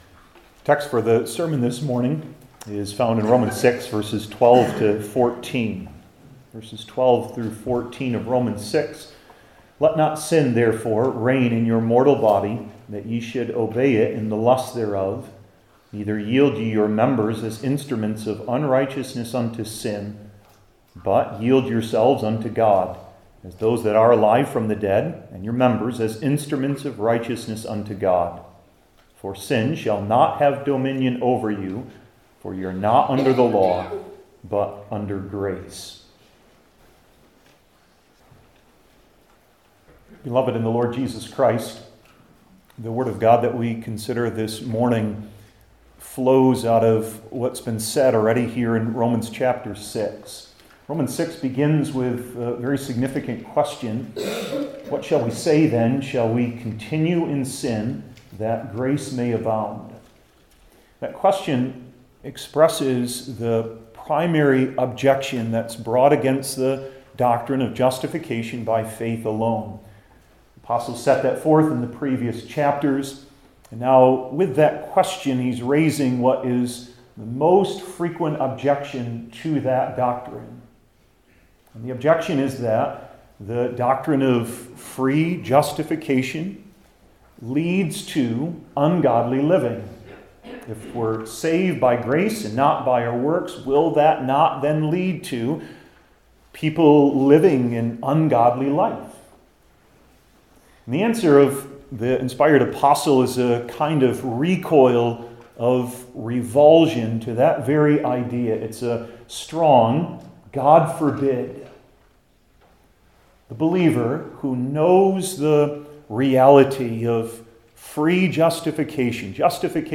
New Testament Individual Sermons I. What Is Forbidden II.